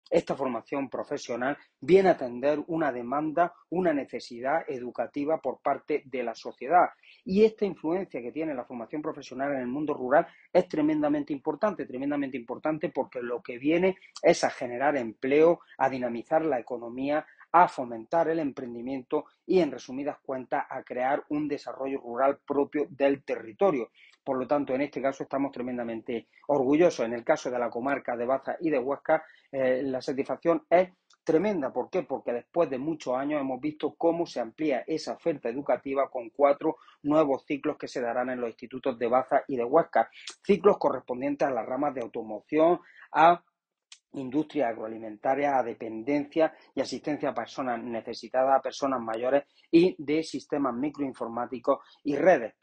El Partido Popular destaca el aumento de ciclos formativos de FP en Baza y Huéscar y el  parlamentario Mariano García celebra que traerá desarrollo en las zonas rurales. Temas que destacan en el comunicado que nos ha remitido el PP granadino y que reproducimos a continuación, junto a la nota de voz que lo acompaña, para ponerlos a disposición de nuestros lectores: